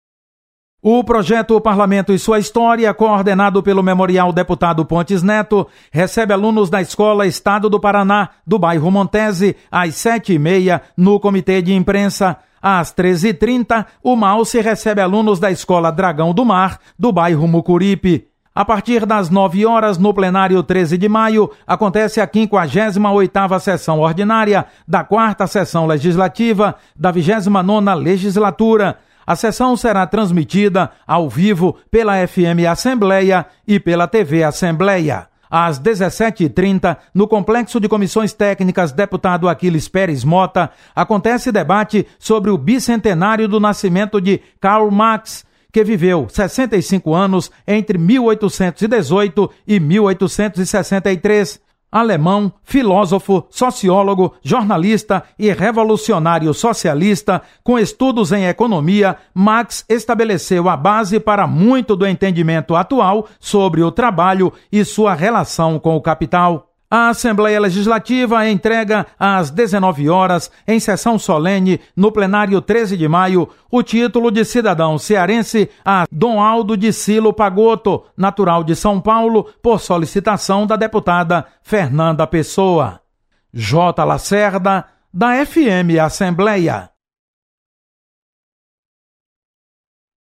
Acompanhe as informações das atividades desta quinta-feira na Assembleia Legislativa. Repórter